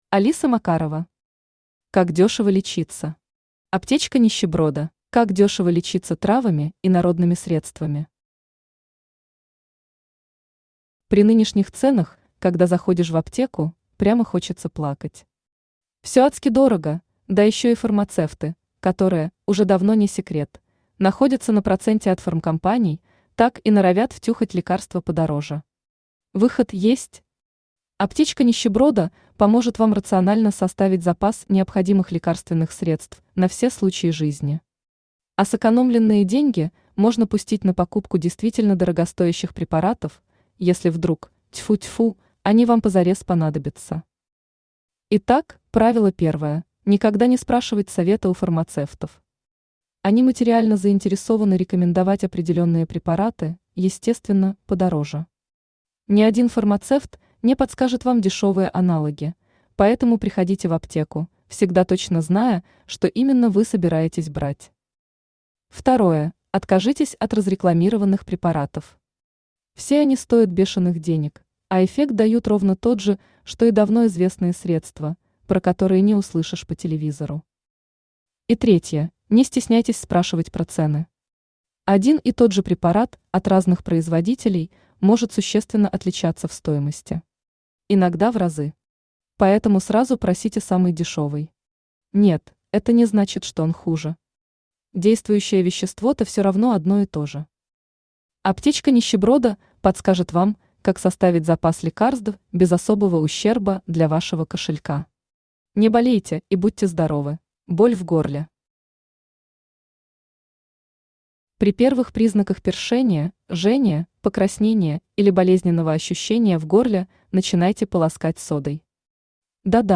Аптечка нищеброда Автор Алиса Макарова Читает аудиокнигу Авточтец ЛитРес.